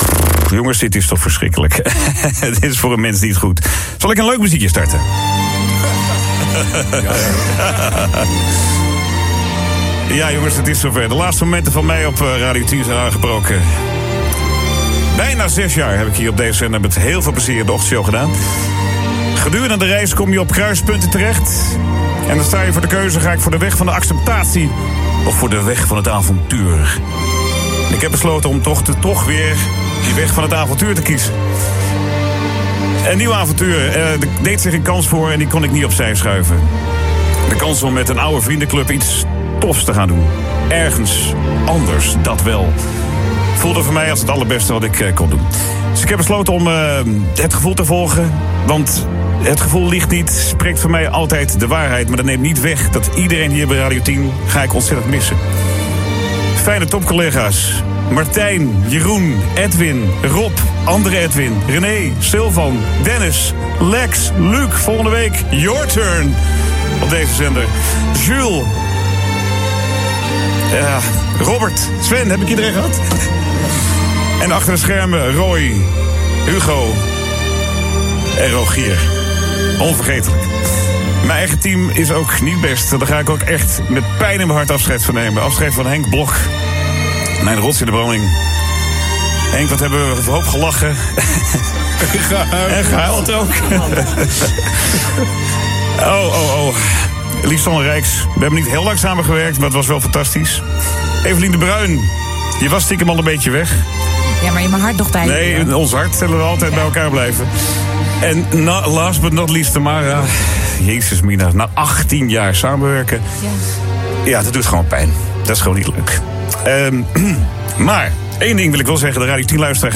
Gerard Ekdom heeft vrijdagochtend zijn laatste ochtendshow op Radio 10 gepresenteerd.
En toch voelt het goed om de volgende stap te gaan nemen”, aldus een geëmotioneerde Ekdom.
Laatste-woorden-Gerard-Ekdom-op-Radio-10.mp3